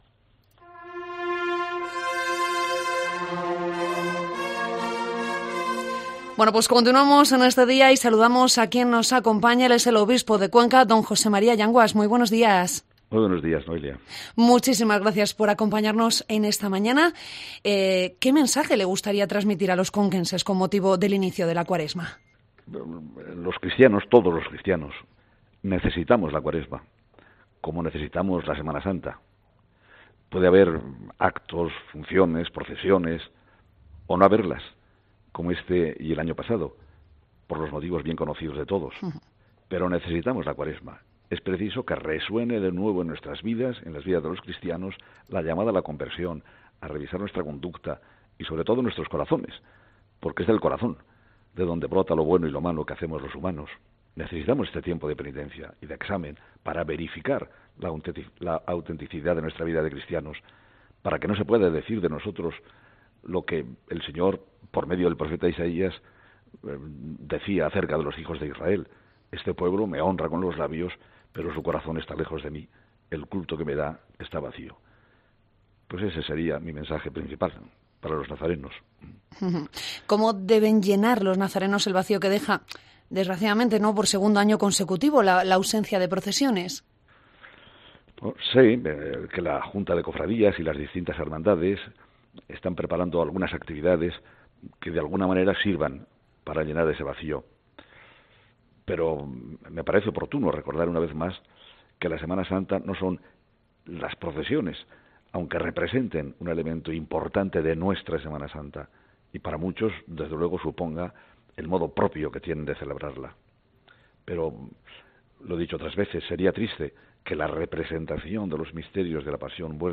AUDIO DE LA ENTREVISTA
Entrevista-Sr.-OBispo-en-Cope-Cuenca-Miercoles-de-Ceniza.mp3